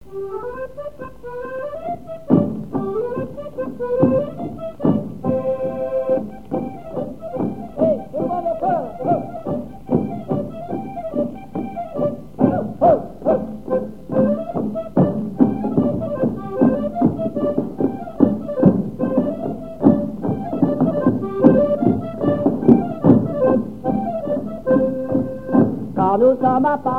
Mémoires et Patrimoines vivants - RaddO est une base de données d'archives iconographiques et sonores.
danse : ronde : grand'danse
airs de danses issus de groupes folkloriques locaux
Pièce musicale inédite